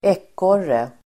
Ladda ner uttalet
Folkets service: ekorre ekorre substantiv, squirrel Uttal: [²'ek:år:e] Böjningar: ekorren, ekorrar Definition: liten gnagare som klättrar i träd Sammansättningar: ekorrskinn squirrel substantiv, ekorre